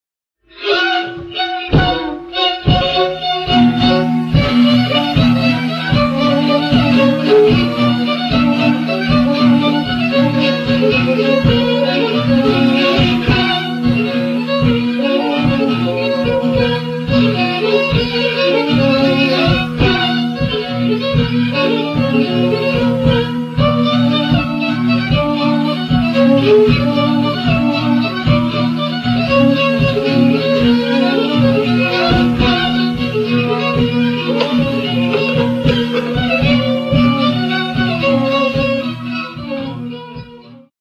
Oberek
instrumentarium: skrzypce, altówka, basy, baraban